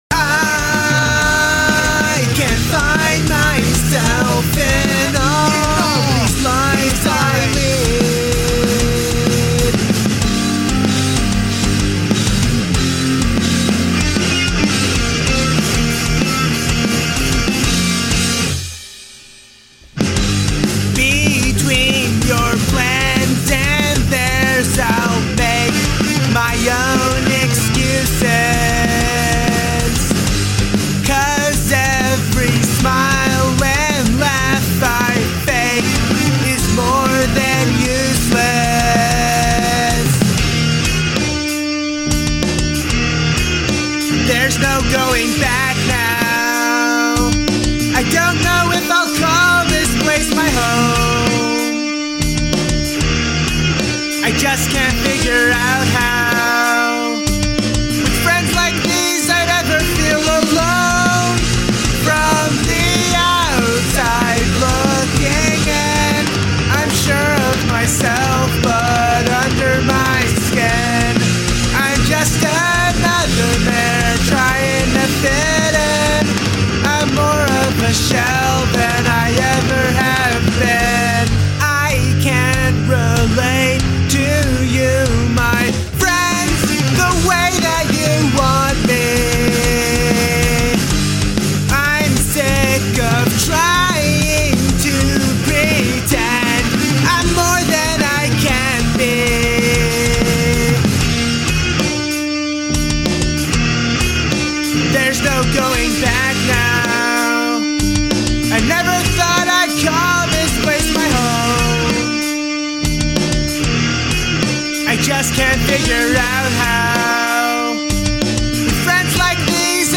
The drums, guitars, vocals, bass, and synth were much more complex than I've ever done, and now that I know more about mixing, I think this definitely surpasses my first song (dat bad quality) as the best pop punk song I have up. I started sort of mid, went pretty heavy in my second song, and am now probably at the most light I'll get.